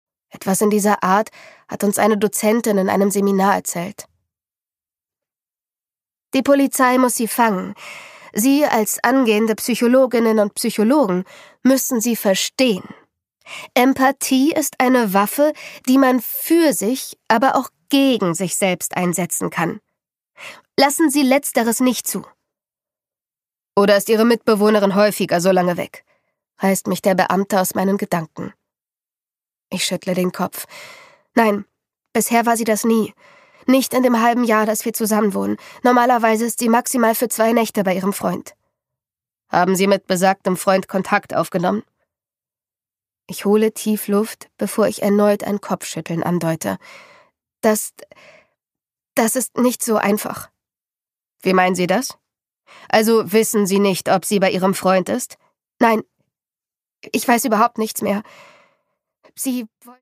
Produkttyp: Hörbuch-Download
MP3 Hörbuch-Download